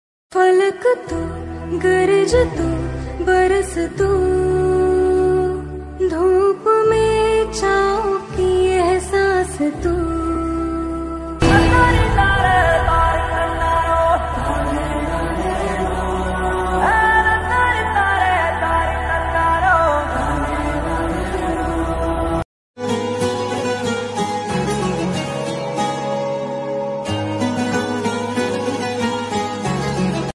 ringtones Hindi songs ringtone